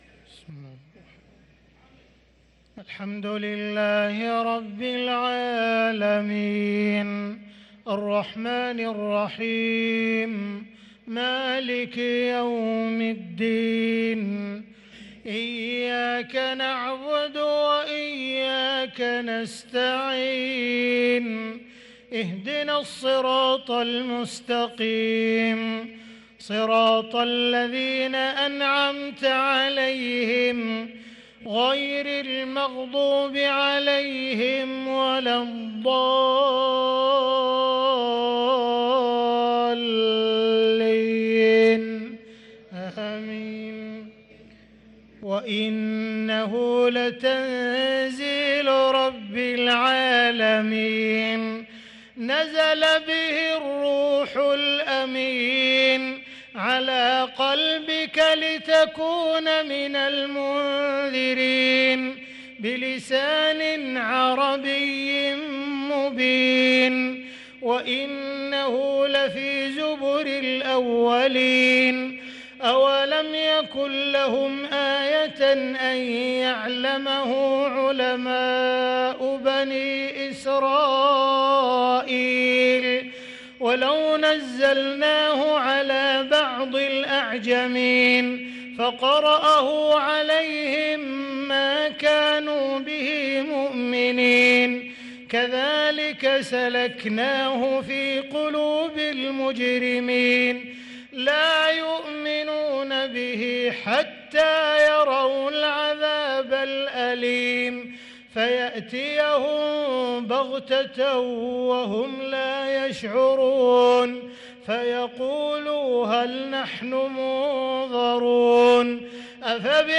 صلاة العشاء للقارئ عبدالرحمن السديس 5 جمادي الآخر 1445 هـ
تِلَاوَات الْحَرَمَيْن .